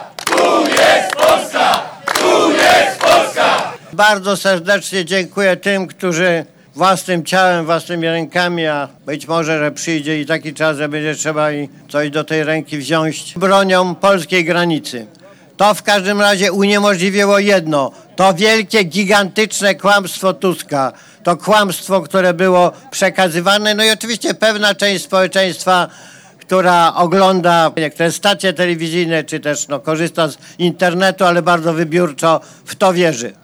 Jarosław Kaczyński odwiedził w niedzielę polsko-niemiecką granicę w Rosówku, gdzie mówił o polityce migracyjnej oraz działaniach podejmowanych przez rząd Donalda Tuska. W trakcie przemówienia prezes Prawa i Sprawiedliwości zwrócił uwagę na kontrole graniczne i ich możliwe cele.